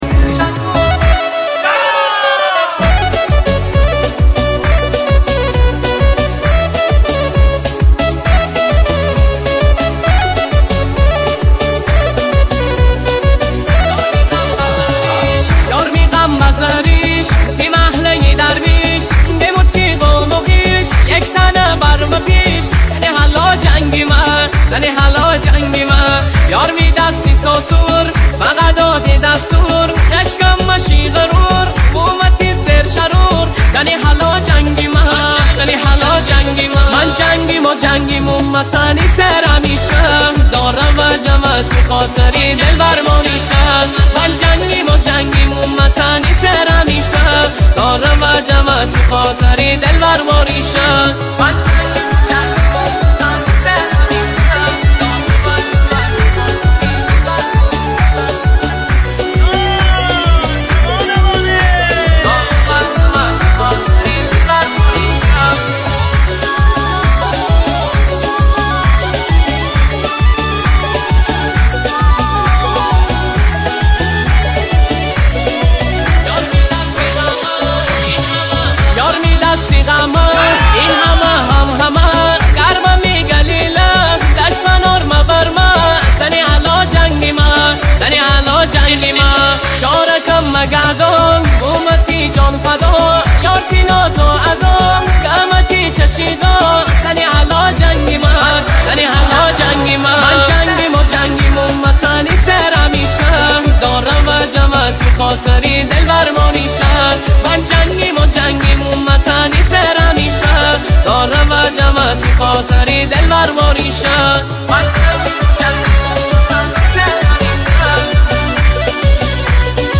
New trend DJ Remix slowed reverb song